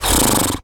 pgs/Assets/Audio/Animal_Impersonations/horse_breath_02.wav at master
horse_breath_02.wav